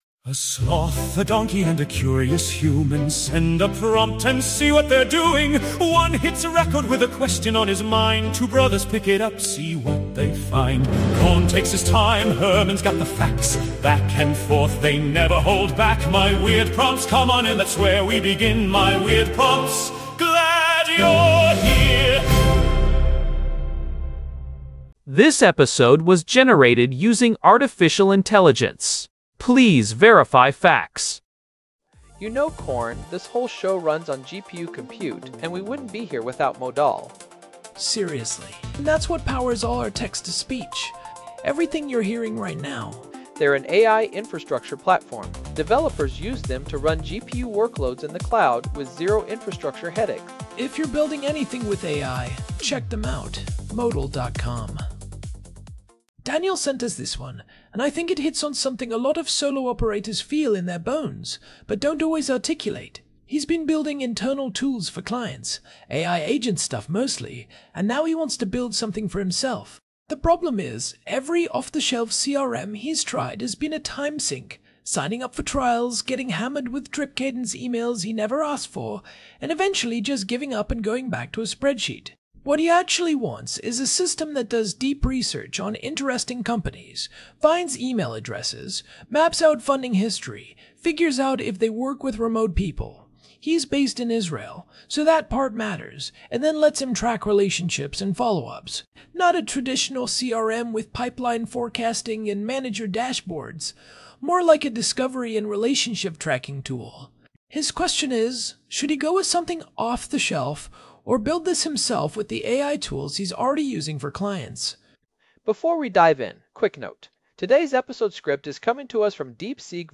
AI-Generated Content: This podcast is created using AI personas.